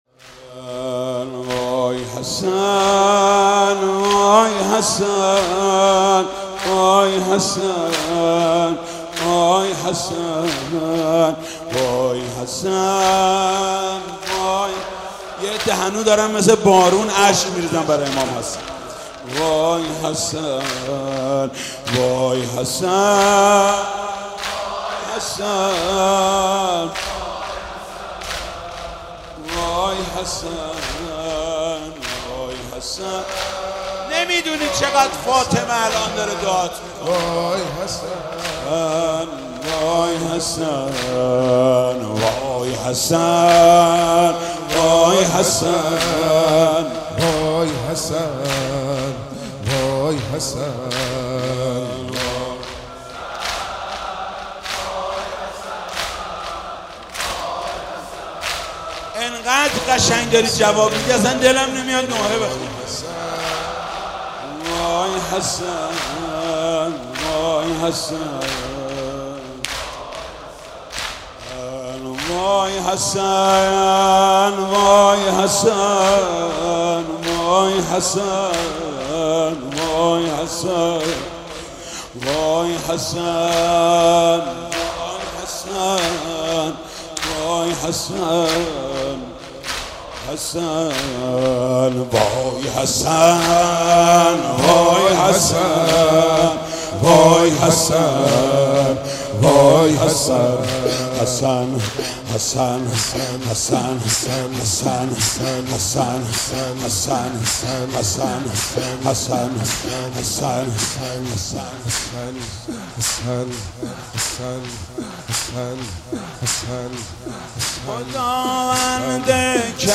«شهادت امام حسن 1393» زمینه: خداوند کرمی، تمام باورمی